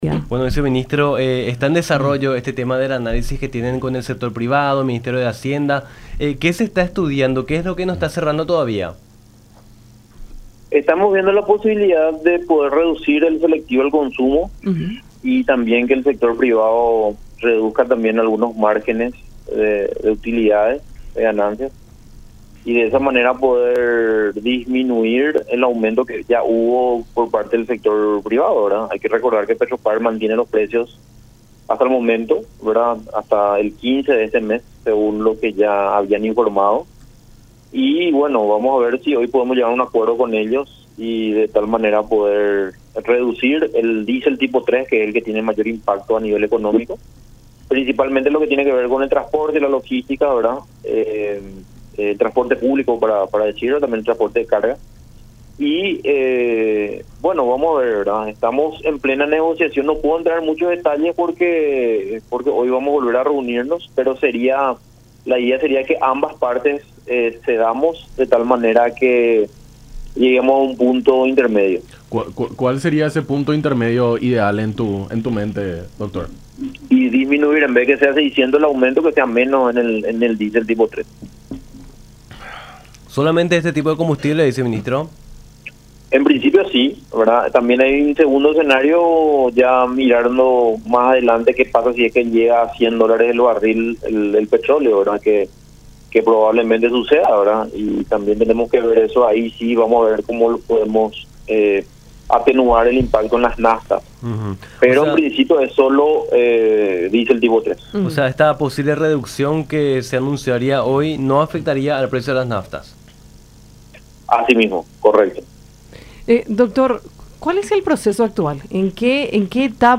“De momento, PETROPAR mantiene sus precios y el tema está ahora en poder llegar a un acuerdo con el sector privado. Estamos en plena etapa de negociaciones y esperamos poder alcanzarlo y que el aumento del diésel tipo flex que sea menos de G. 600 y atenuar el precio en las naftas (…) Nosotros ya venimos debatiendo con el equipo de Hacienda esta situación y, si se puede reducir, sería fantástico”, dijo Orué en conversación con Nuestra Mañana por La Unión.